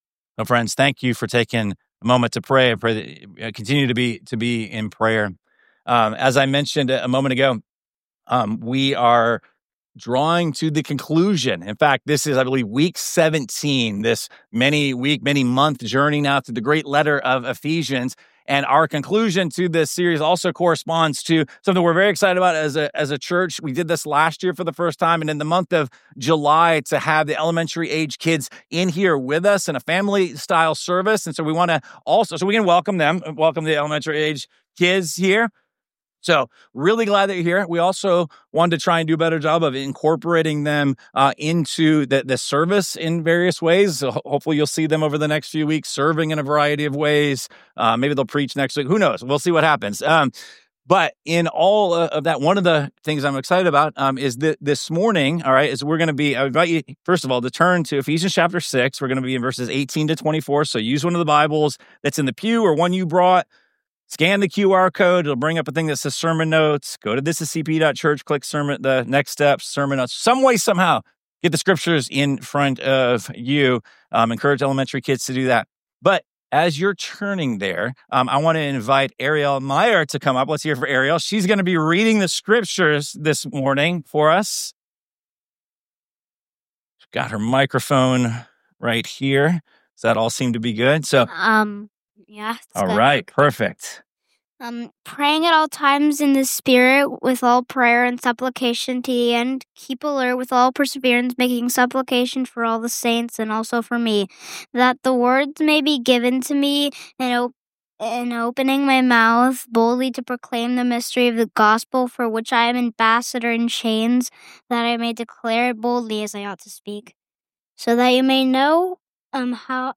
7.6.25 Sermon mastered.mp3